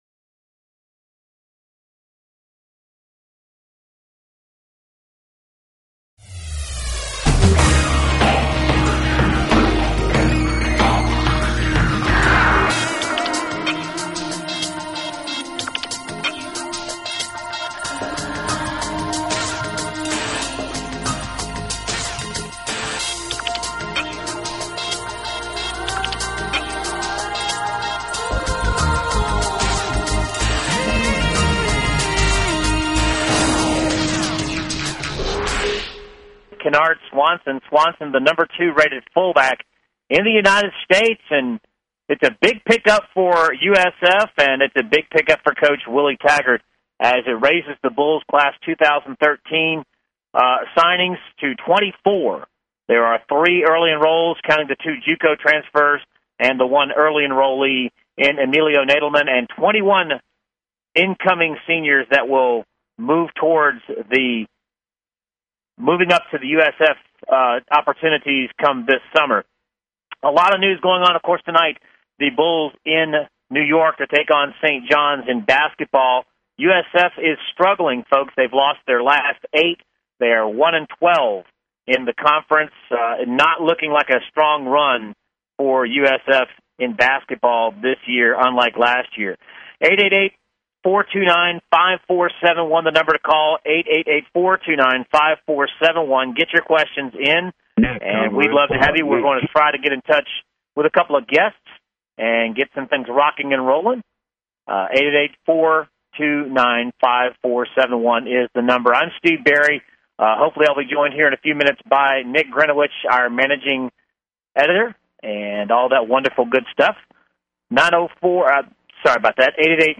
Talk Show Episode, Audio Podcast, The_Bull_Pen and Courtesy of BBS Radio on , show guests , about , categorized as